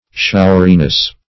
Showeriness \Show"er*i*ness\, n. Quality of being showery.
showeriness.mp3